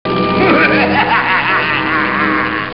evil-laugh.wav